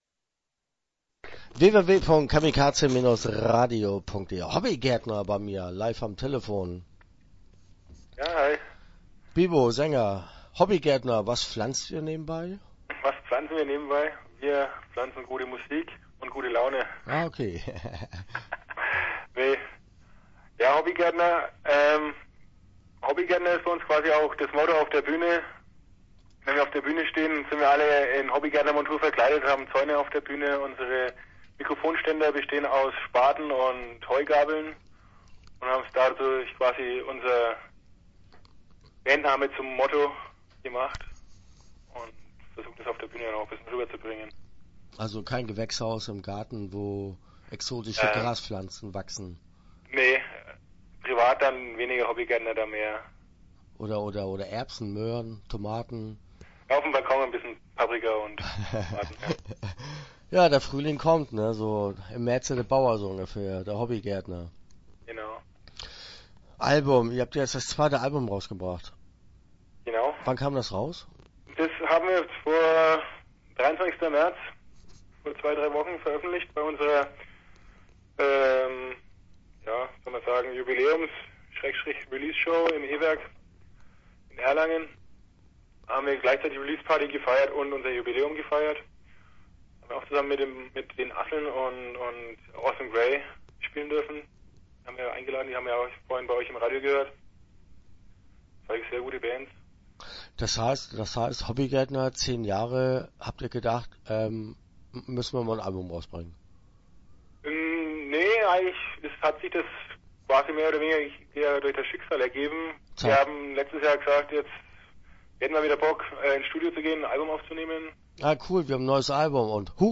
Start » Interviews » Hobbygärtner